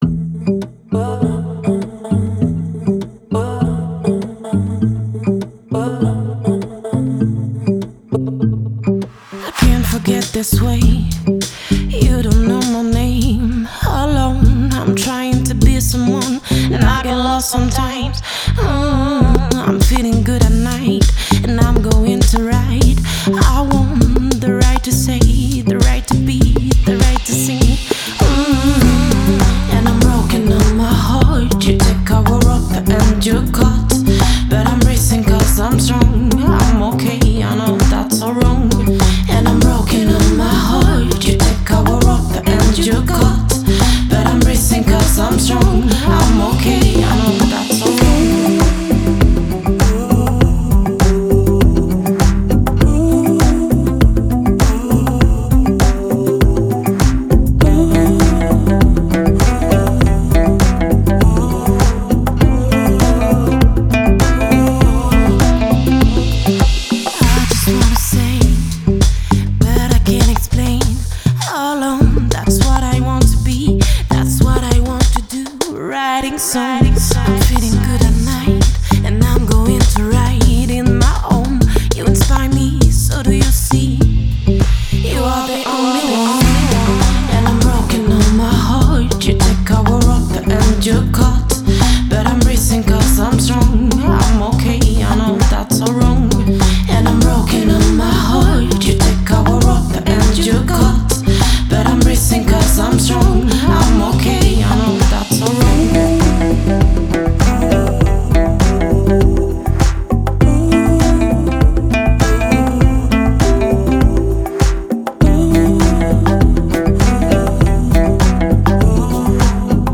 это эмоциональная песня в жанре инди-поп